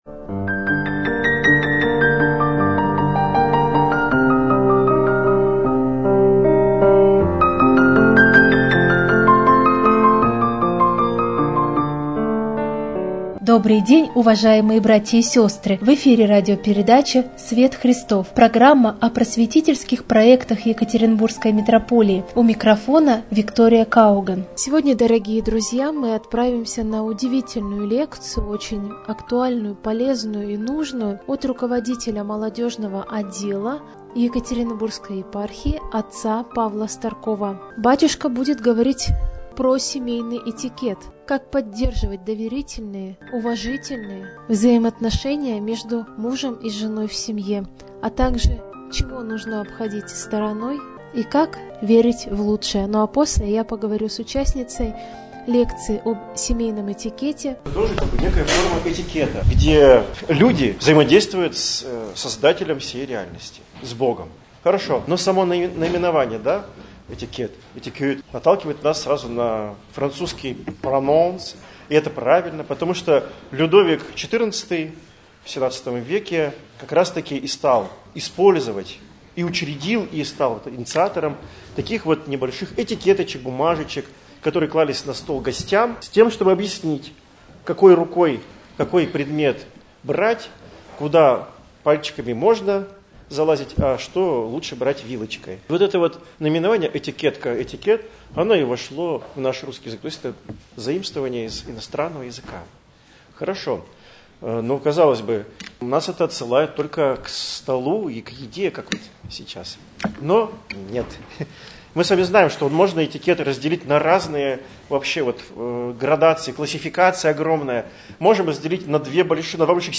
lekciya_o_semejnom_etikete.mp3